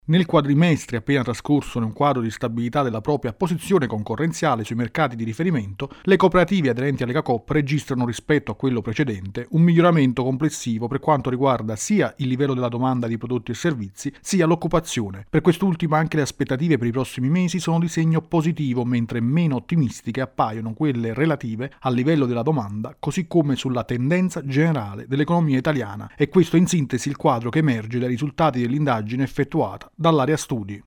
In graduale ripresa – Aumento della fiducia e dell’occupazione nelle cooperative aderenti a Legacoop. Il servizio